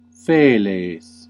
Ääntäminen
UK : IPA : /ˈkæt/ US : IPA : /ˈkæt/